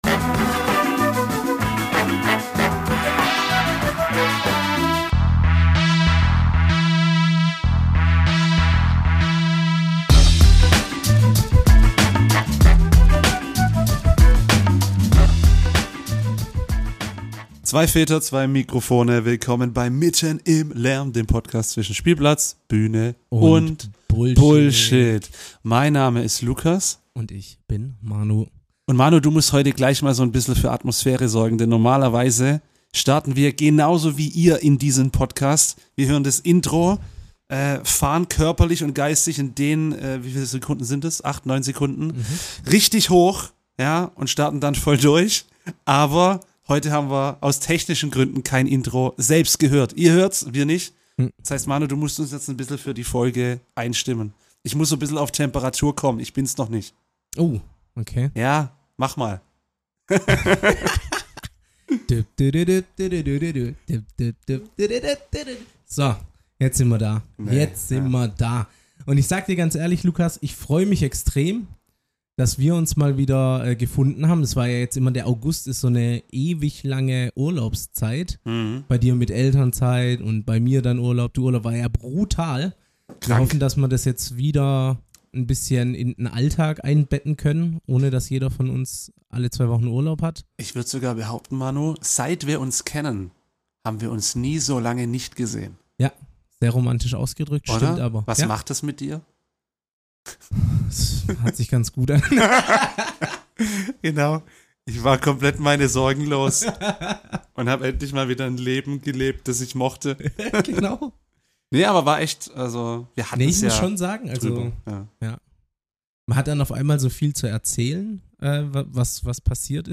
Außerdem geht es mal wieder um Fußball – genauer gesagt um die Millionensummen, für die inzwischen selbst durchschnittliche Spieler gehandelt werden. Zum Schluss wird es politisch und nachdenklich: Die beiden diskutieren über die Wehrpflicht und werfen einen Blick darauf, wie sie ihre bisherigen Lebensentscheidungen bewerten.